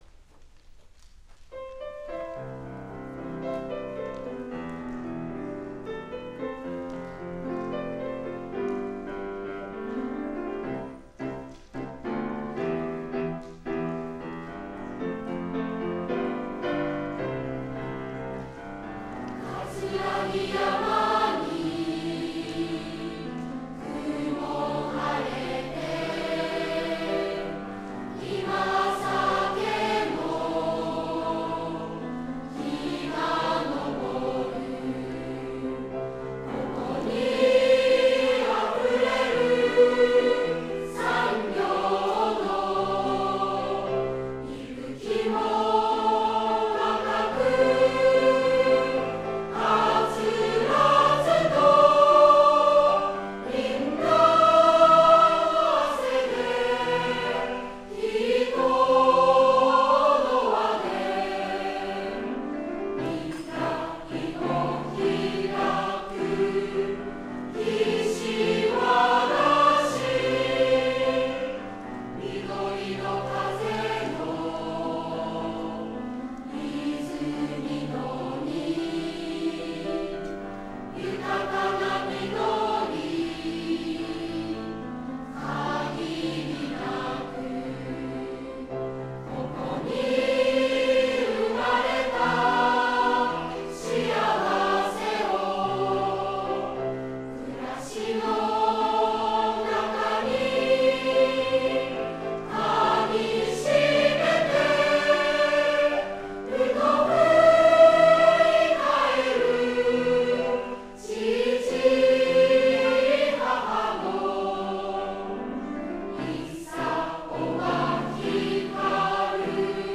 作詞　寺岡　光義
作曲　新井　利昌
「岸和田市讃歌」合唱（2012年7月29日マドカ合唱祭） [wavファイル／7.6MB]